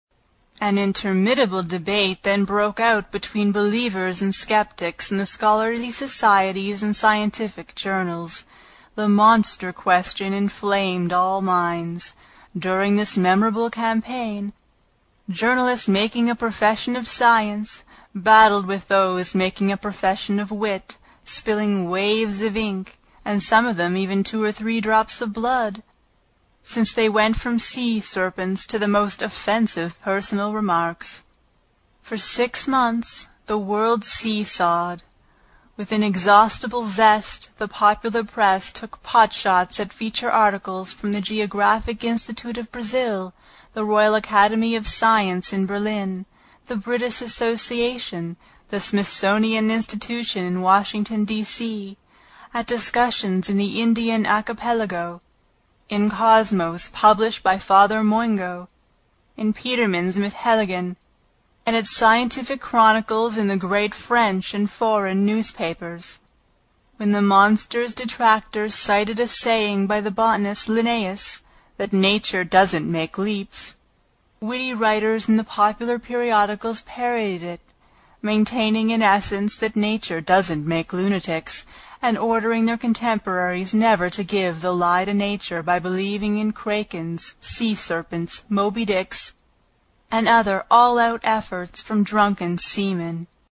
英语听书《海底两万里》第5期 第1章 飞走的暗礁(5) 听力文件下载—在线英语听力室